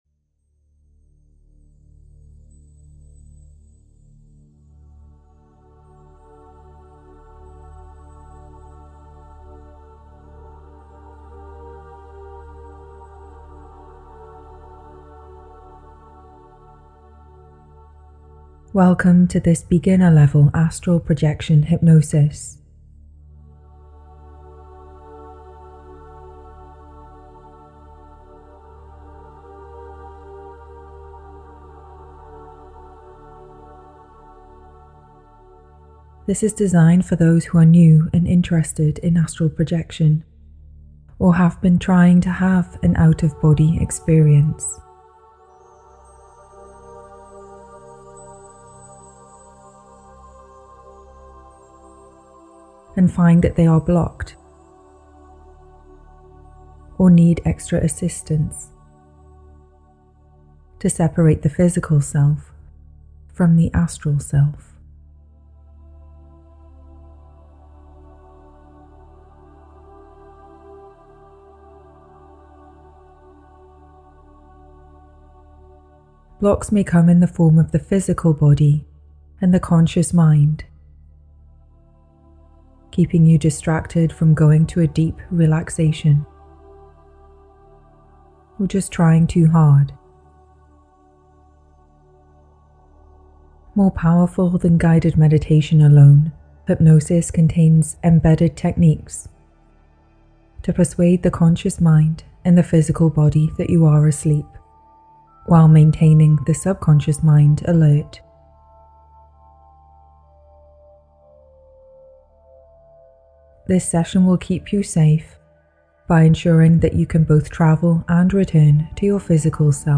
Beginner's Astral Projection OBE Hypnosis / Meditation (Extended Relaxation to Release Astral Self) This astral projection hypnosis and meditation is for those who are new to astral projection or struggle to reach the state of mind to separate the astral self from the physical self.